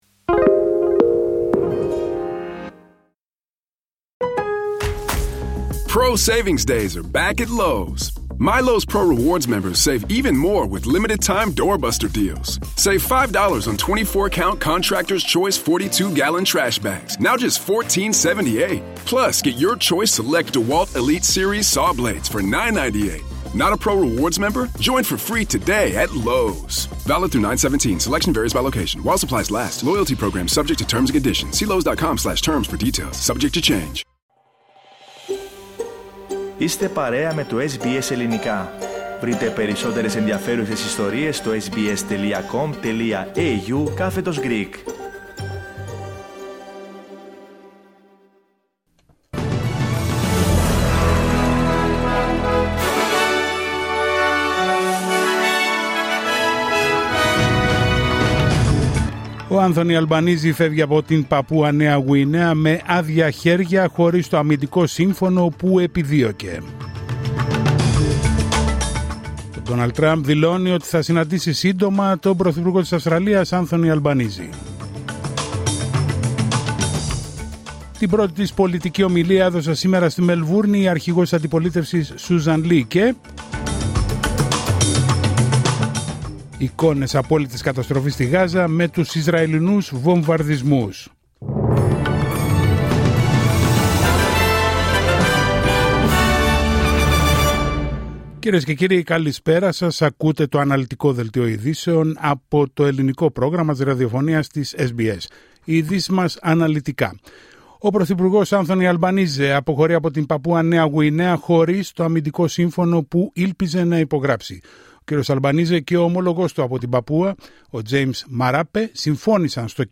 Δελτίο ειδήσεων Τετάρτη 17 Σεπτεμβρίου 2025